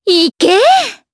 Shamilla-Vox_Attack3_jp.wav